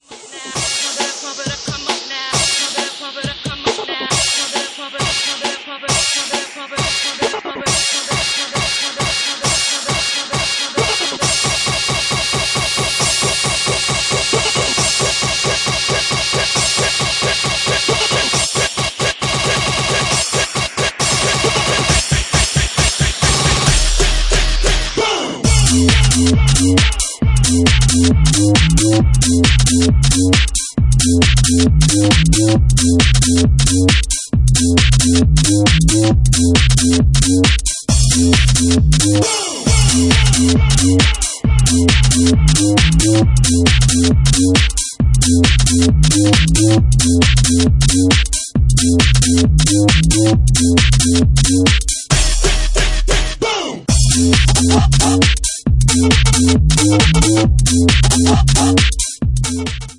Genre:Bassline House
Bassline House at 136 bpm